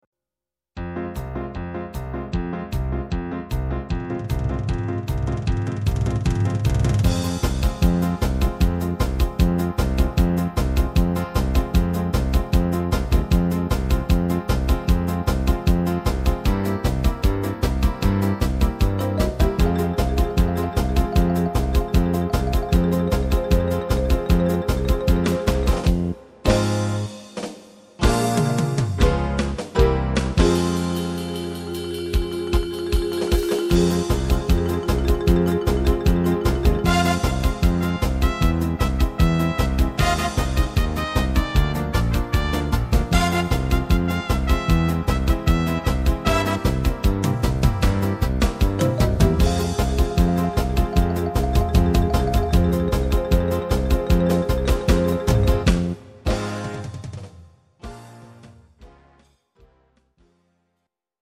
instrumental Trompete